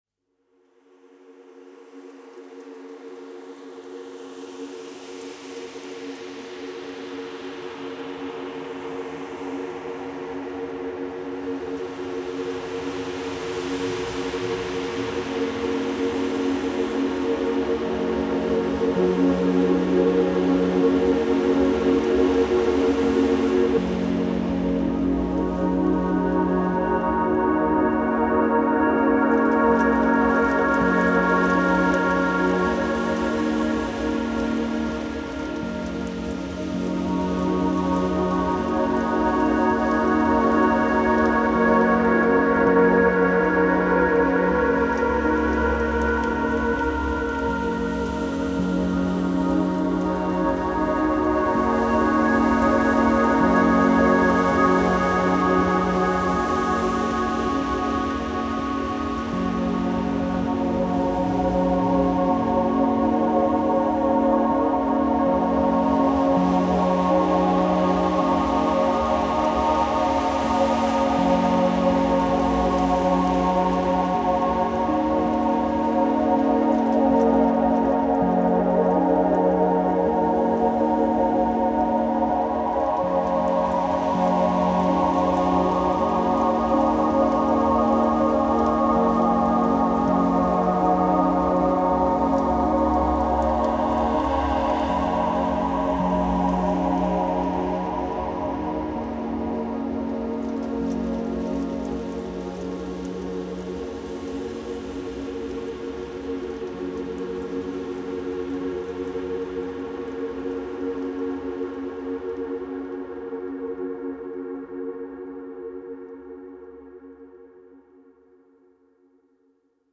Drift through tranquil and warm synth landscapes.